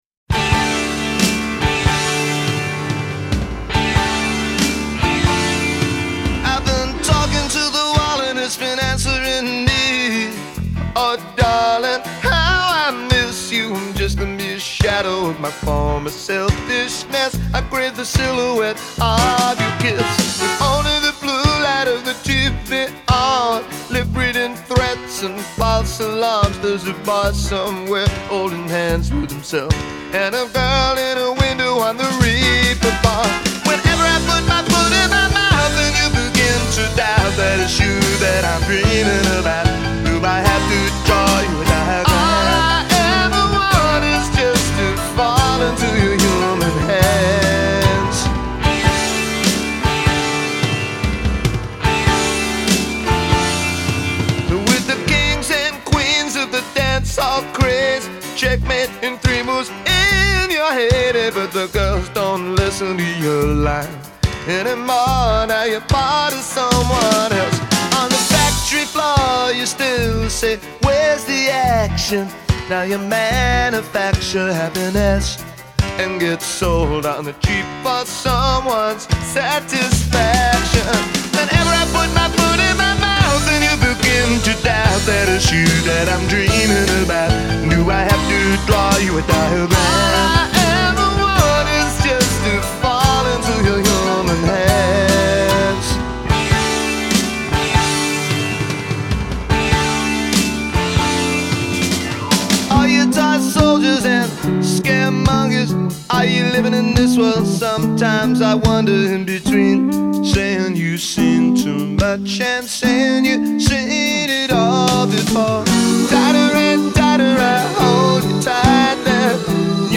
love songs